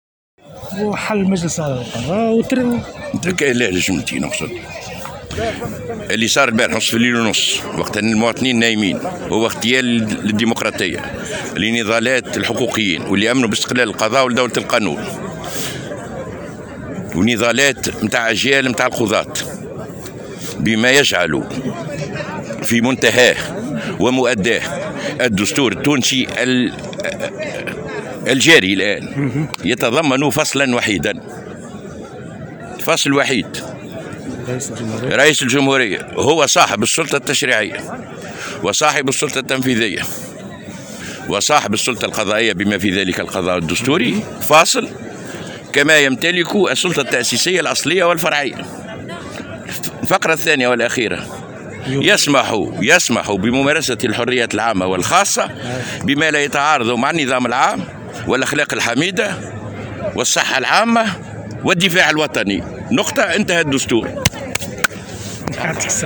في تصريح
الى ساحة حقوق الانسان بتونس العاصمة بمناسبة احياء الذكرى 9 لاغتيال الشهيد شكري بلعيد